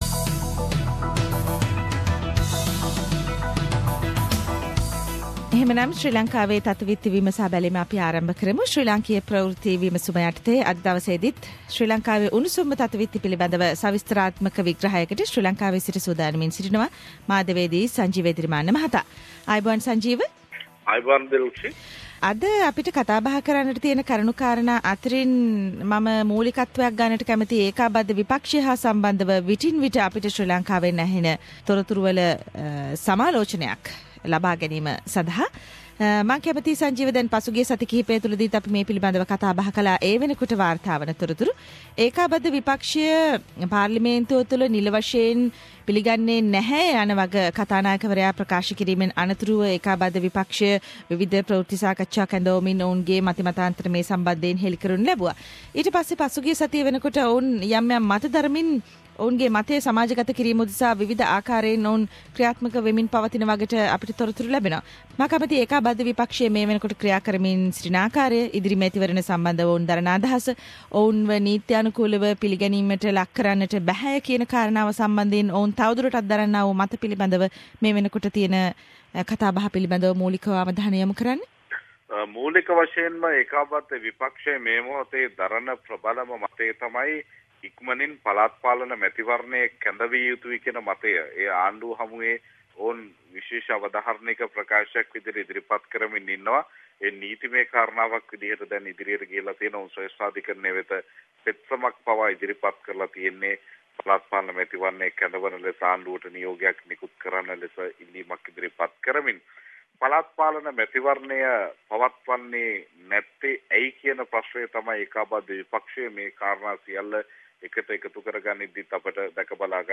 reports from Sri Lanka